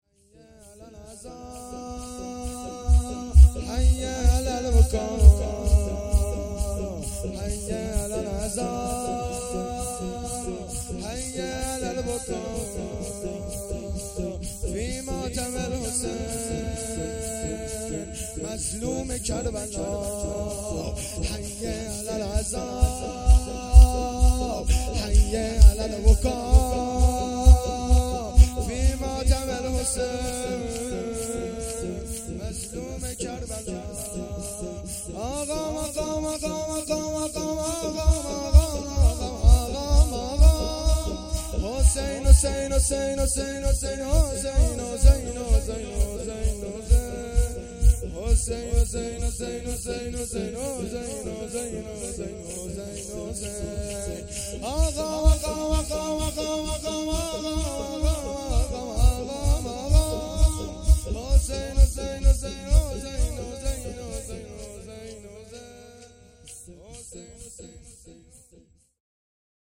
شور
شب اول محرم الحرام ۱۴۴۳